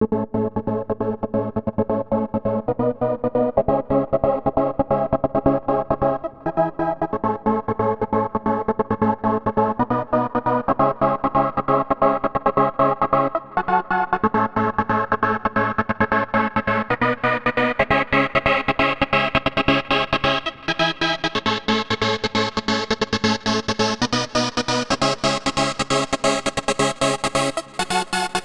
TI CK7 135 Det Chord1.wav